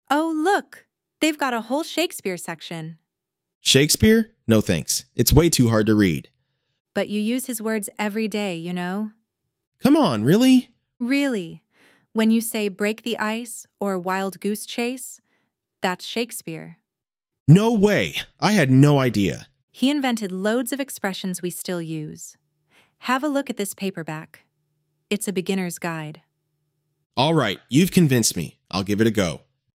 🎭 Dialogue — At the Bookshop
ElevenLabs_Shakespeare_EduMNC.mp3